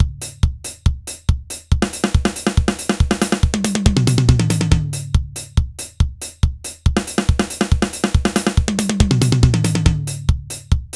Mne sa zdaju dobre ciste. User1 = Bass Drum / kopak User2 = Snare / rytmicak User3 = HiHat User4 - 6 = prechodaky (Tom) Cinely sa mi nevosli, v buducej varke.
Samply su vo Wav a musim ich trochu zdegradovat aby sa dali napchat do Hammerheadu ale zvuk je slusny, sak posudte.